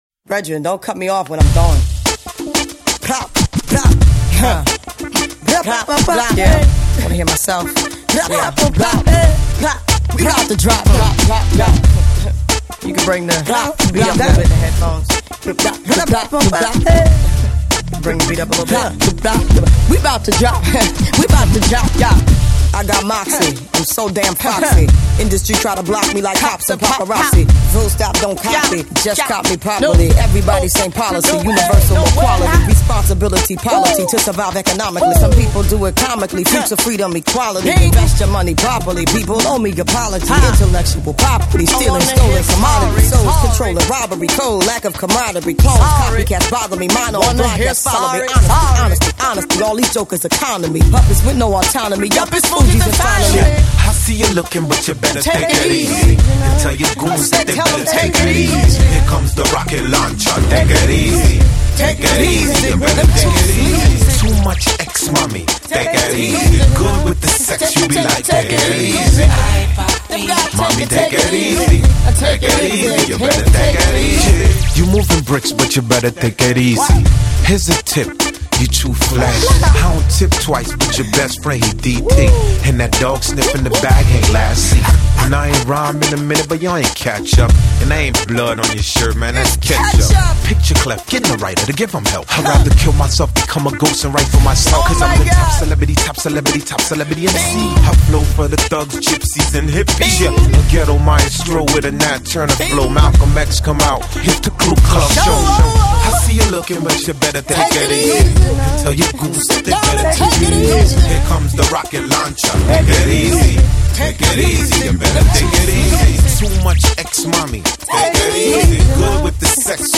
an intriguing blend of jazz-rap, R&B, and reggae